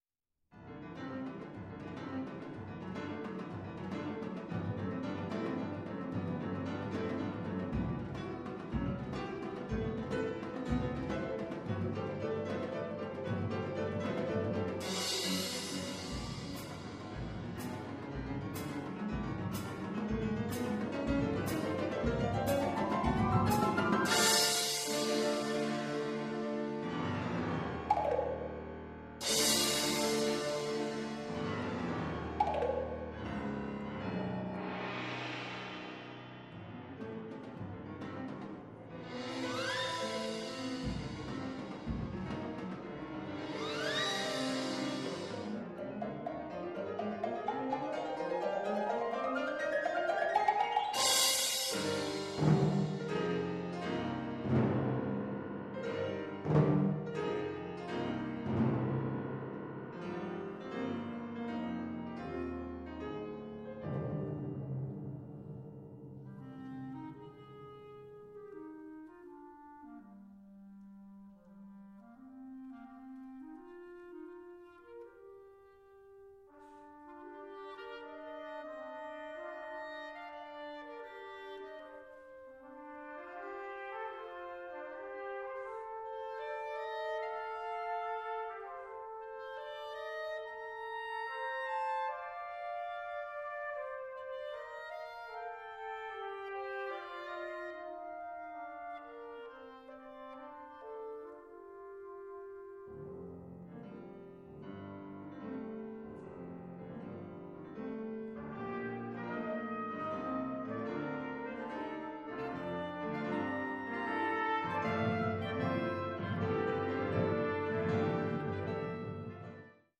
piano
clarinet
trumpet
percussion